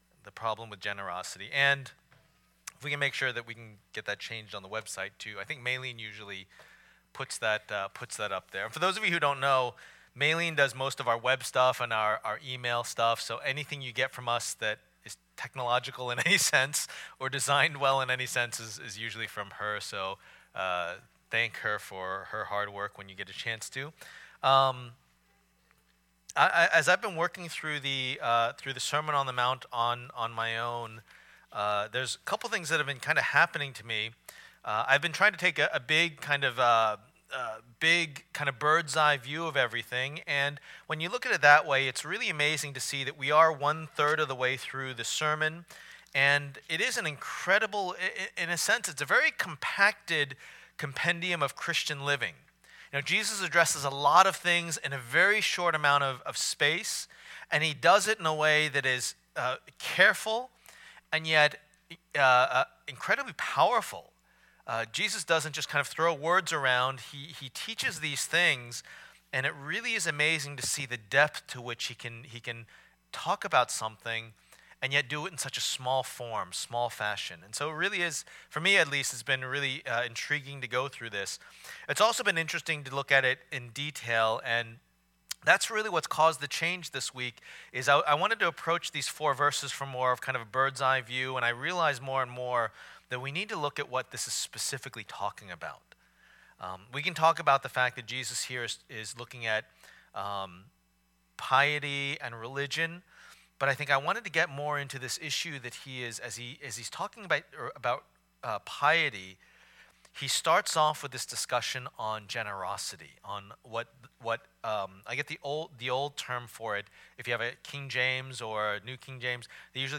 Passage: Matthew 6:1-4 Service Type: Lord's Day